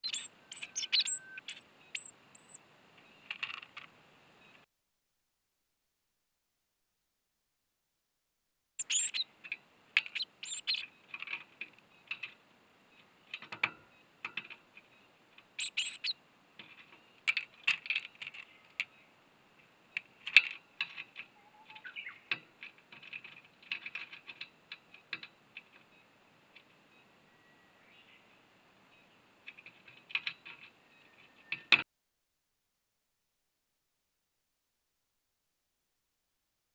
巣立ち後７２日経過した若鳥が単独行動での採餌中のため周り に聞き手があるわけではないが様々な音で鳴いていた。
多くの不完全な単語を羅列する鳴き方をすることがあったが、そ の中にはサエズリの部分もあった。
Ybird.wav